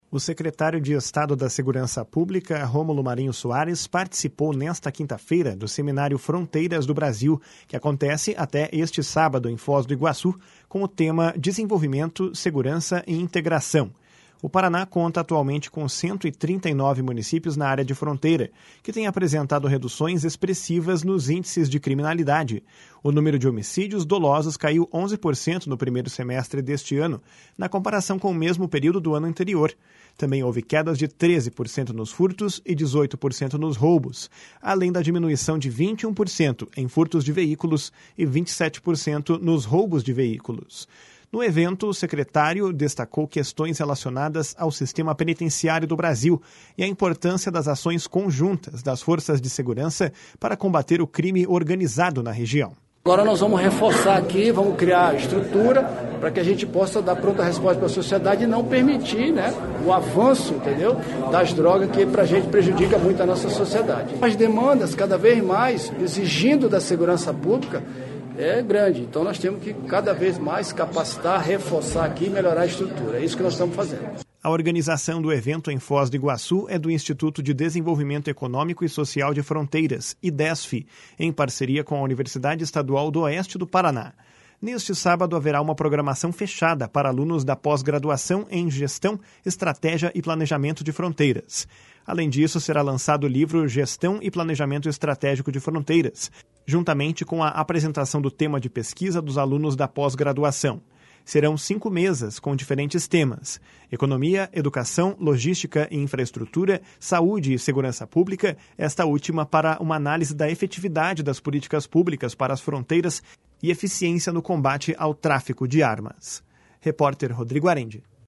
No evento, o secretário destacou questões relacionadas ao sistema penitenciário no país e a importância das ações conjuntas das forças de segurança para combater o crime organizado na região. // SONORA ROMULO MARINHO //